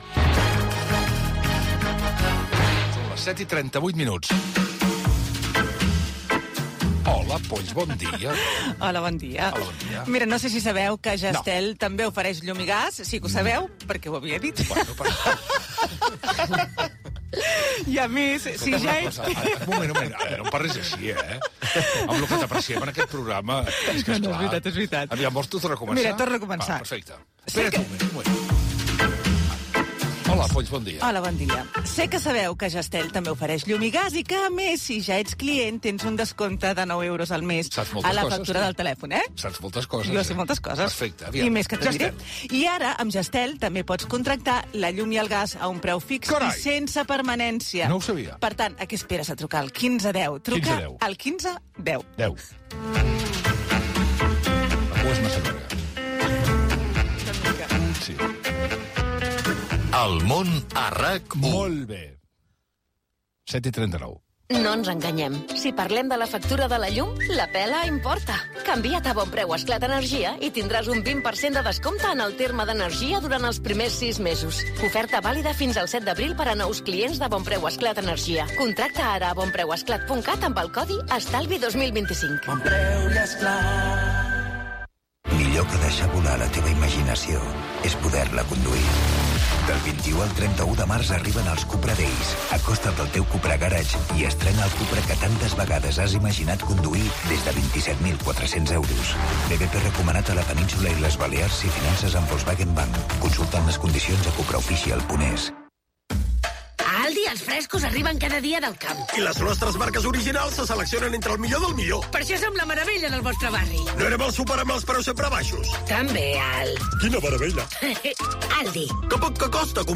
Hora, espai publicitari, indicatiu, hora, publicitat, número guanyador de l'Euro Jackpot de l'ONCE i indicatiu
Info-entreteniment
FM